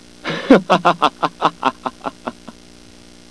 Sephiroth laughs!
This was recorded from Ehrgeiz, in which one of Sephiroth's moves is a "taunt," in which he laughs arrogantly at his pathetic little opponent.
sephylaugh.wav